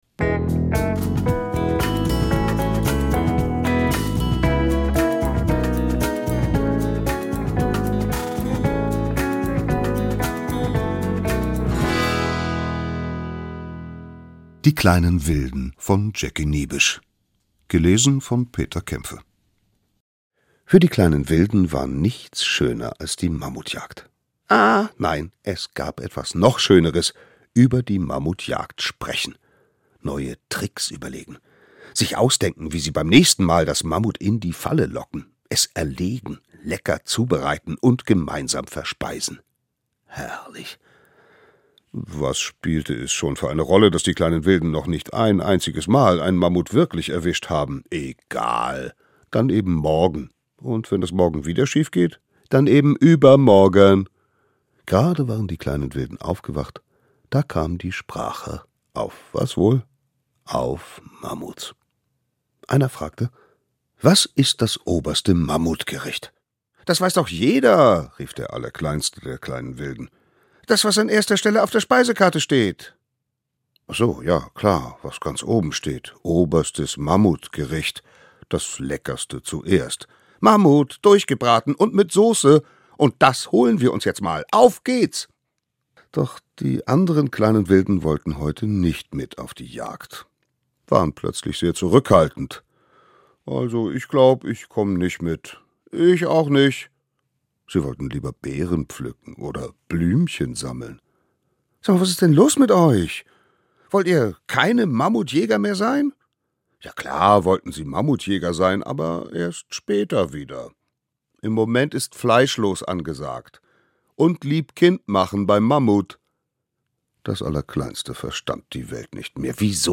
Lesung für Kinder: Die kleinen Wilden und das oberste Mammutgericht ~ Hörspiele, Geschichten und Märchen für Kinder | Mikado Podcast